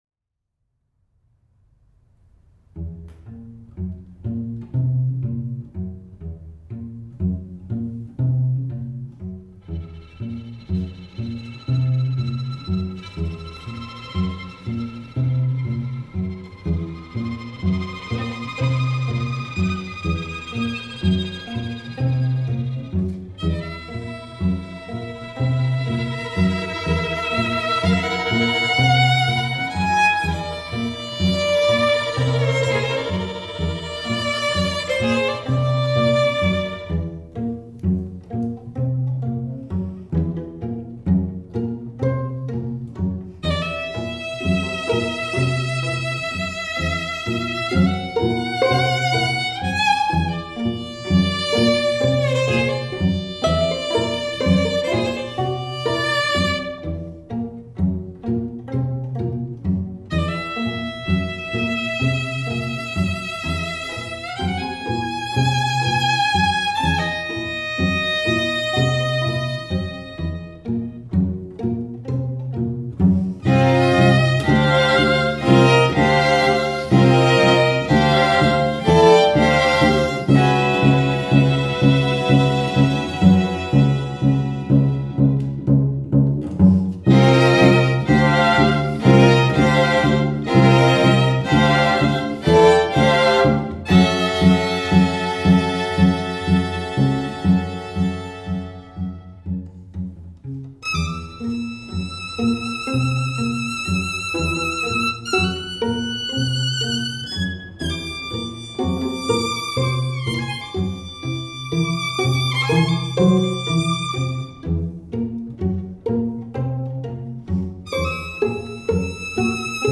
·        Mood: festive, joyful, Mexican, energetic, rhythmic
The orchestration is sparse and symbolic.
String Quartet